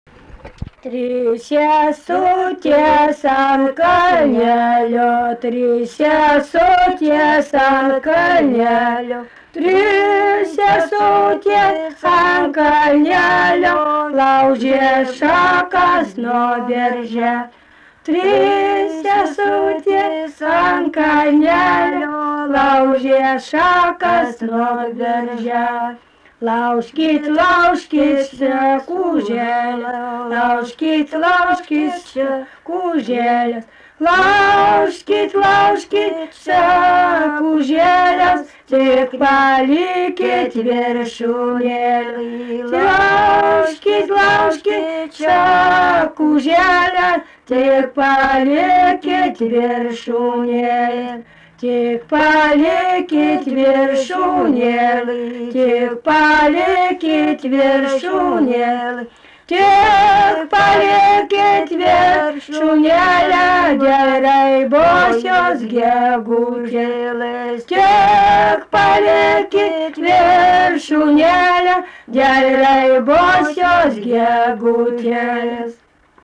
daina
Rudnia
vokalinis